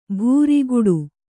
♪ bhūriguḍu